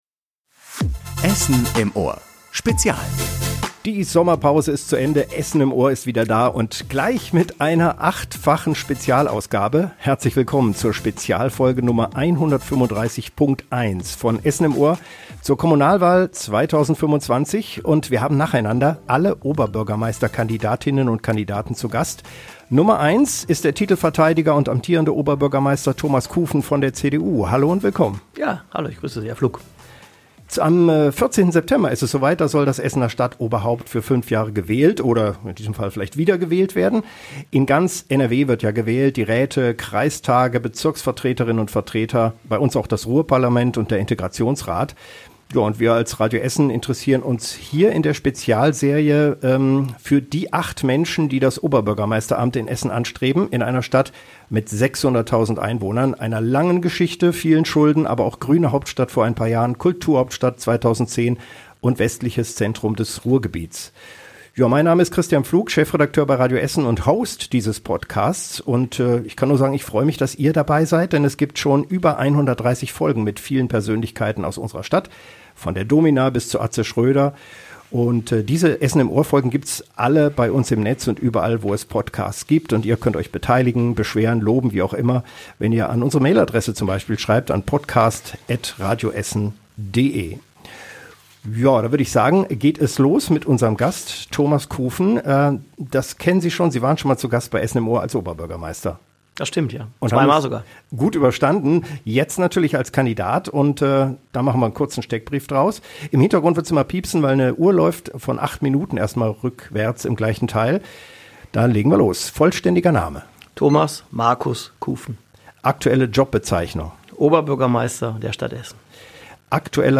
#135.1 mit OB-Kandidat Thomas Kufen (CDU) ~ Essen im Ohr - Der Talk mit Persönlichkeiten aus der Stadt Podcast